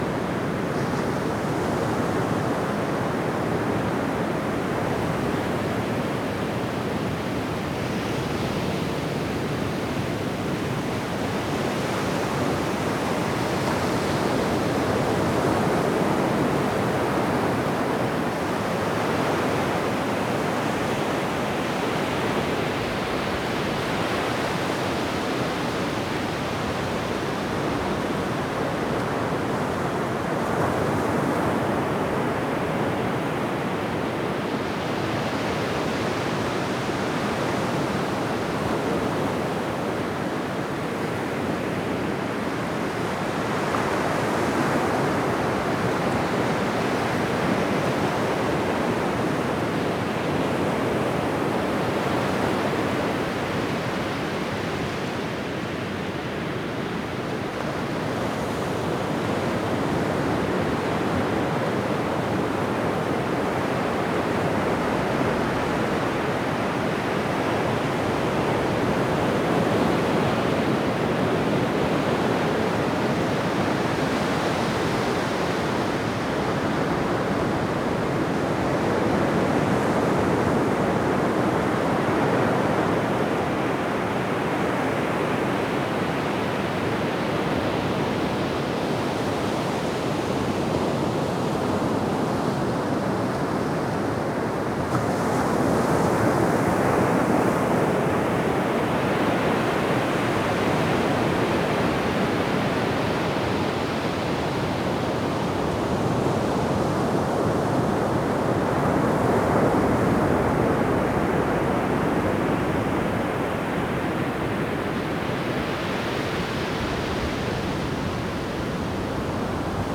ocean-4.ogg